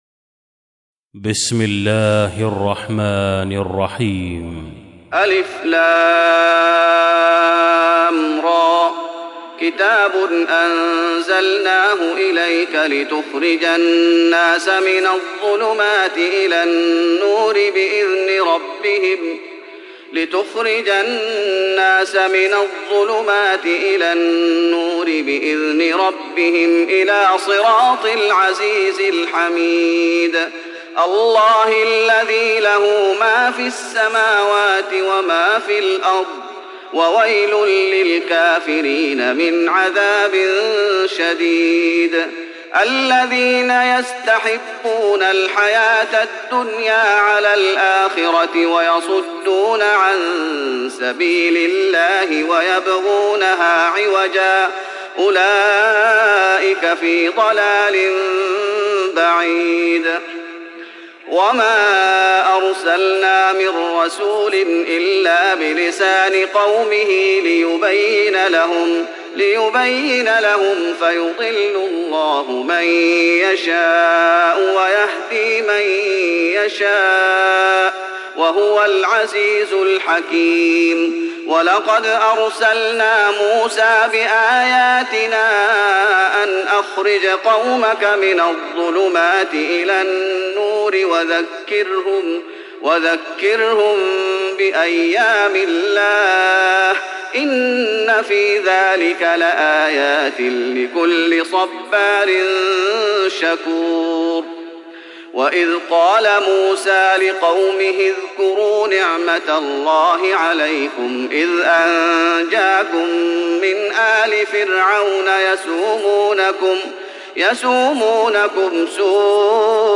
تراويح رمضان 1415هـ سورة إبراهيم Taraweeh Ramadan 1415H from Surah Ibrahim > تراويح الشيخ محمد أيوب بالنبوي 1415 🕌 > التراويح - تلاوات الحرمين